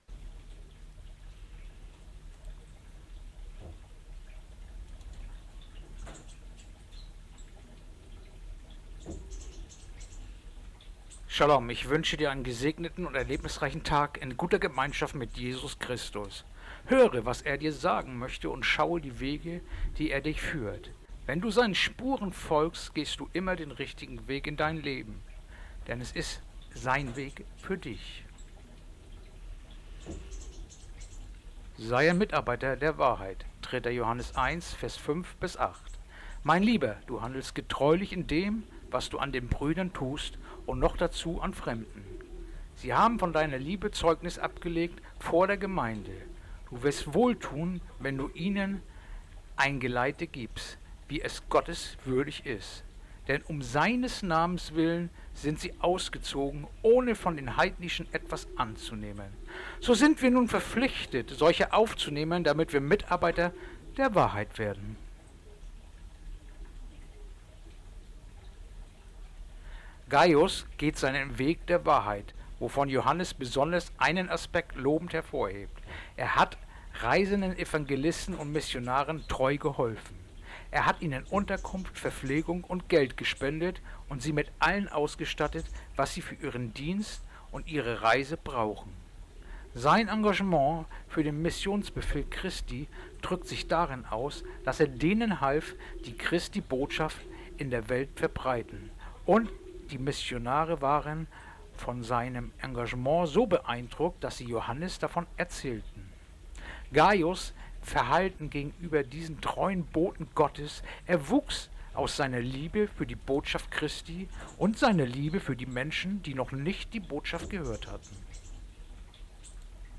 Andacht-vom-21-Juli-3-Johannes-1-5-8